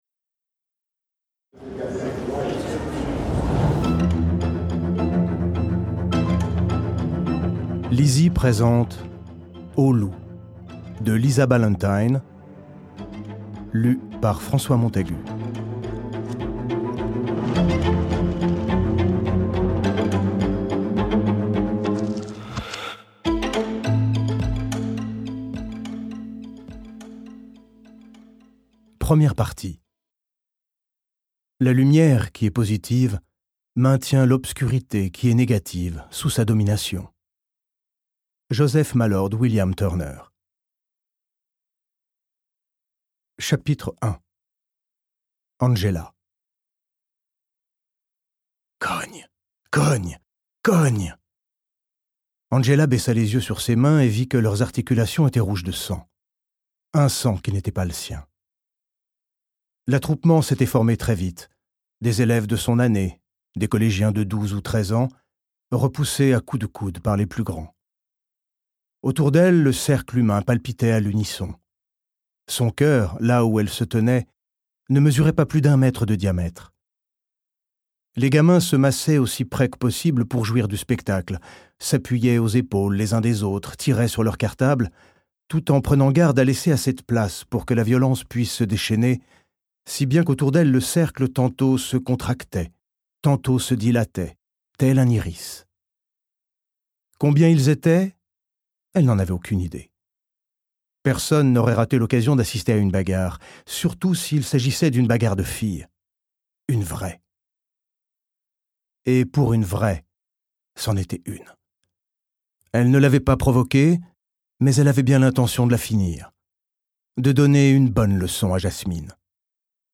Extrait gratuit - Au Loup de Lisa BALLANTYNE